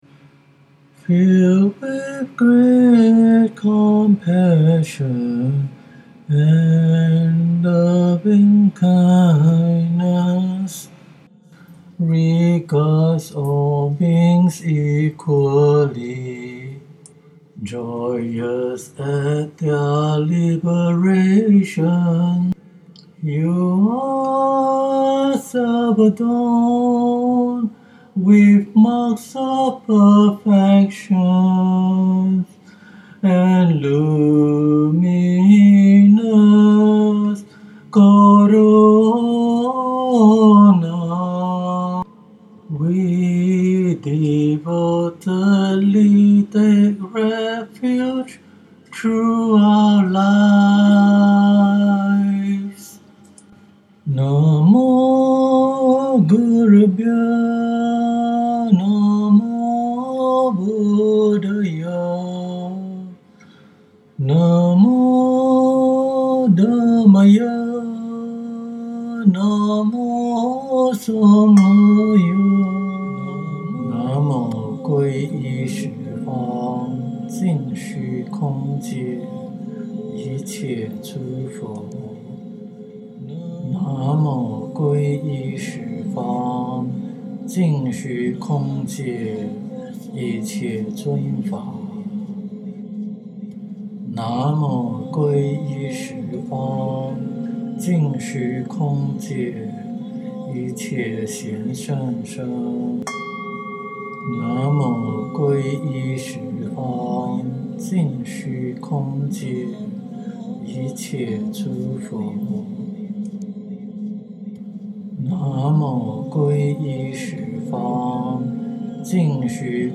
Please pardon my vocal but I just want to try my best to share the tune in my mind. Watch out for the Volume.
Pardon the sound quality too, it’s recorded with a 10 dollar microphone.